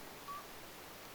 ei tämä varikseltakaan vaikuta,
olisiko kyläpöllönen